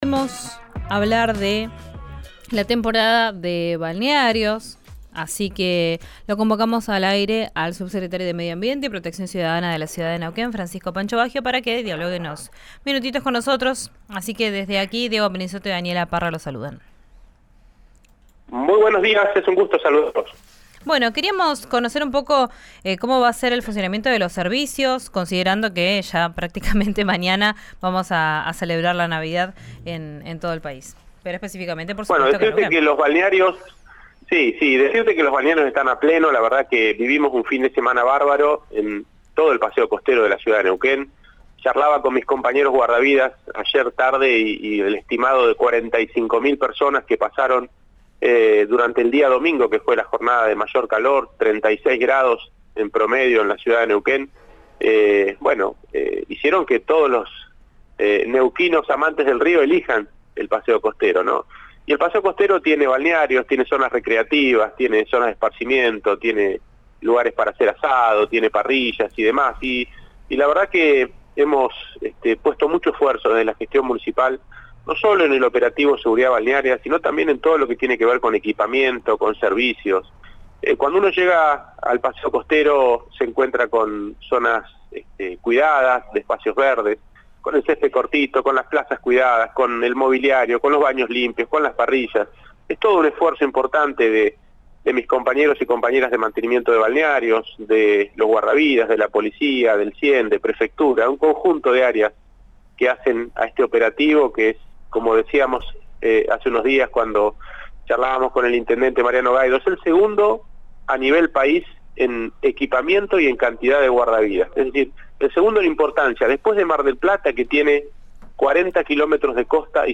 Escucha a Francisco Baggio, Subsecretario de Medioambiente y Protección Ciudadana de Neuquén en RADIO RÍO NEGRO: